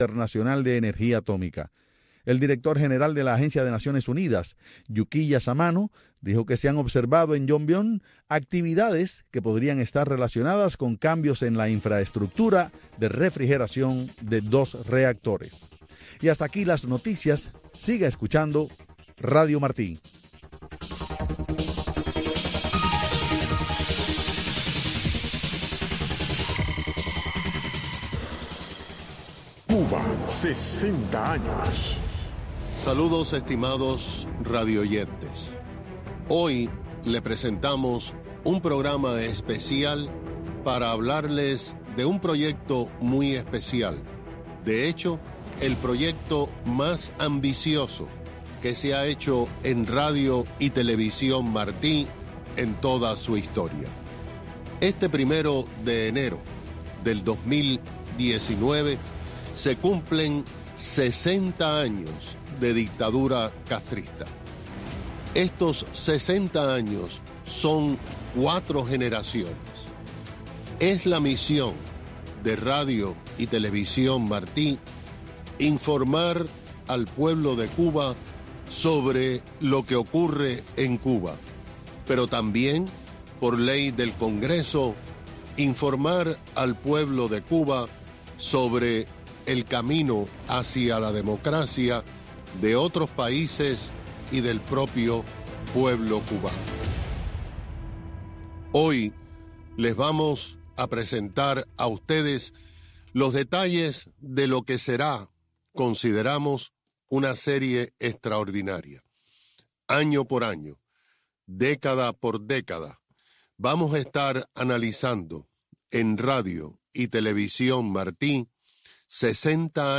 En el programa se explica la segmentación de la serie, los tópicos a tratar, tanto para radio como televisión, así como las entrevistas realizadas a los protagonistas de la historia cubana de estas seis décadas. Se ofrecen segmentos de las intervenciones de los entrevistados, así como los aspectos de la realidad cubana que han sido tergiversados por el régimen, o ignorados.